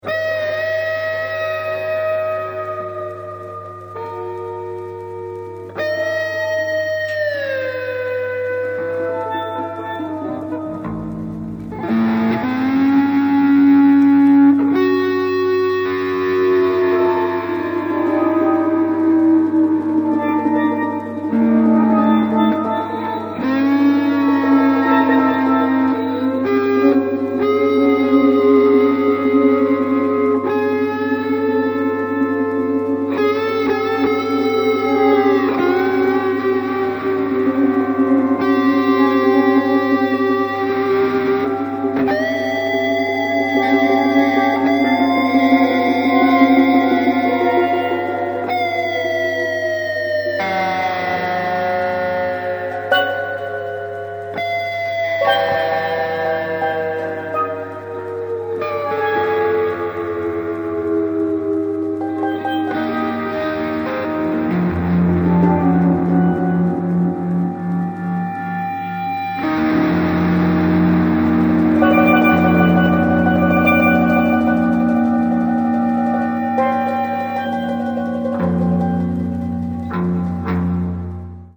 Guitar and Percussion improvisations
drums, steel drums, and percussion
Fender Stratocaster electric guitar
Recorded at Liquid Shack